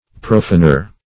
Search Result for " profaner" : The Collaborative International Dictionary of English v.0.48: Profaner \Pro*fan"er\, n. One who treats sacred things with irreverence, or defiles what is holy; one who uses profane language.